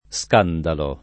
Sk#ndalo] s. m. — antiq. scandolo [